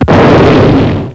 kaboom.mp3